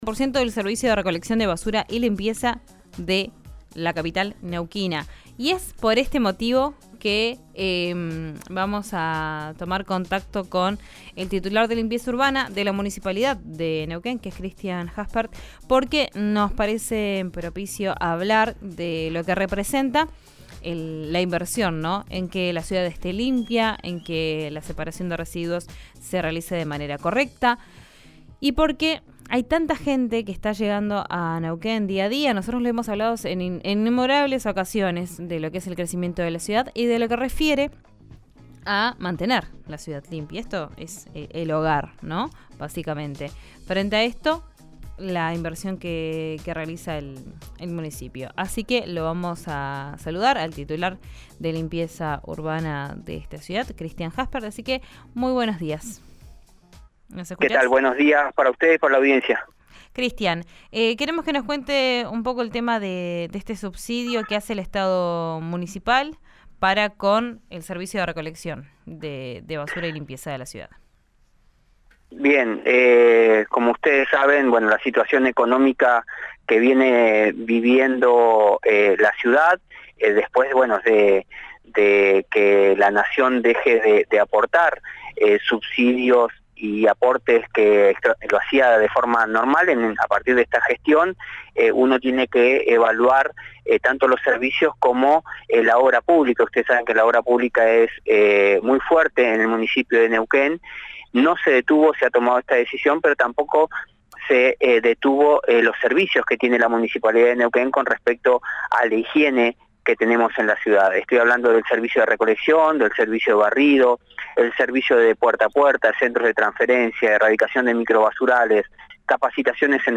Escucha al subsecretario de Limpieza Urbana, Cristian Haspert en »Vos al Aire» por RÍO NEGRO RADIO.